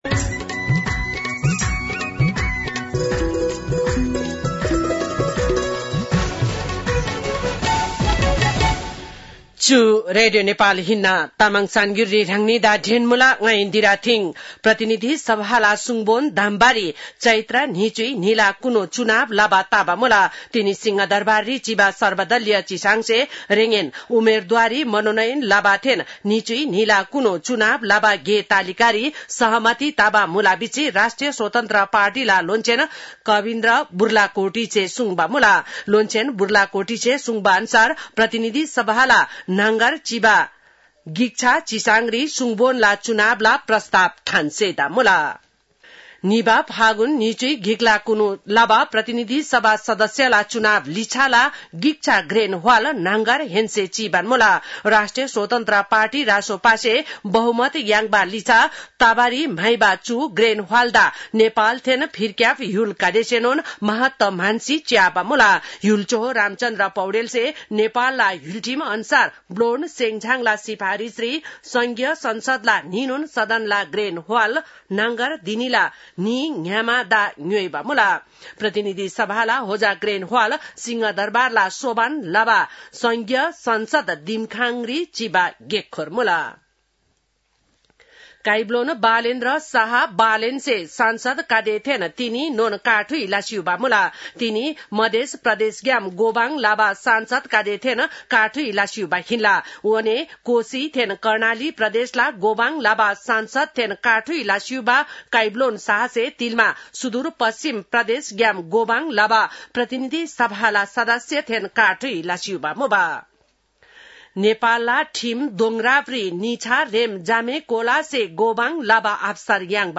तामाङ भाषाको समाचार : १८ चैत , २०८२